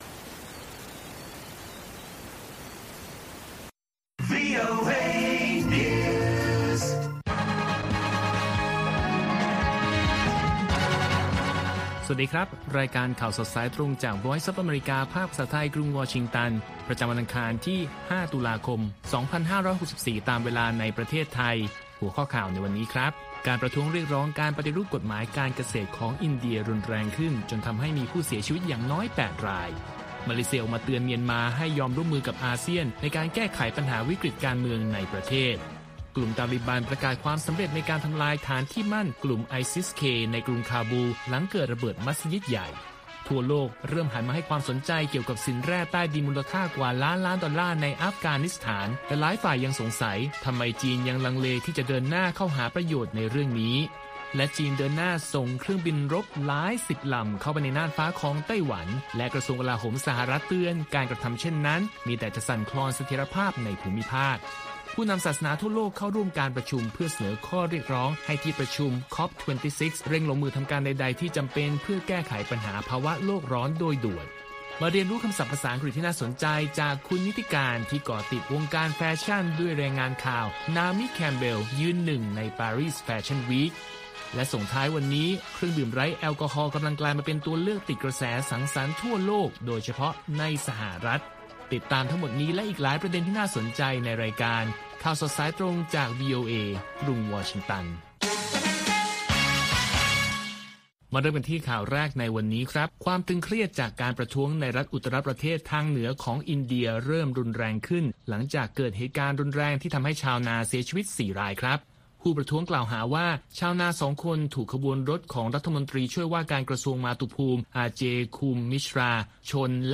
ข่าวสดสายตรงจากวีโอเอ ภาคภาษาไทย ประจำวันอังคารที่ 5 ตุลาคม 2564 ตามเวลาประเทศไทย